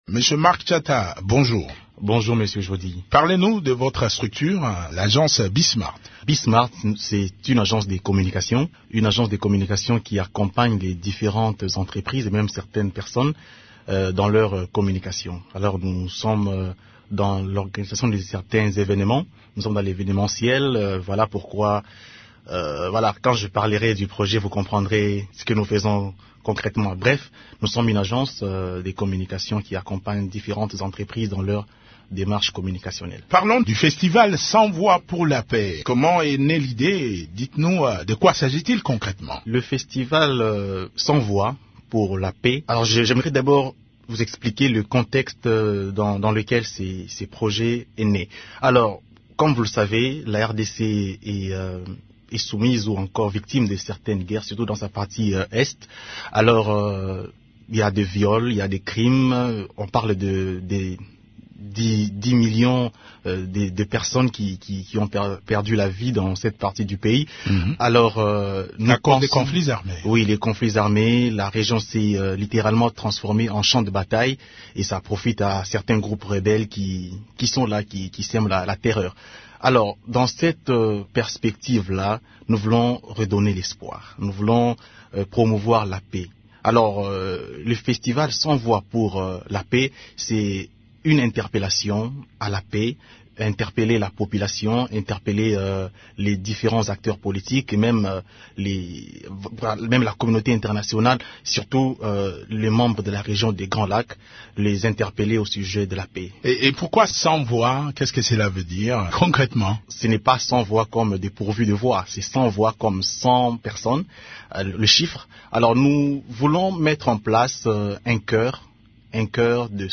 Les détails de ce festival dans cet entretien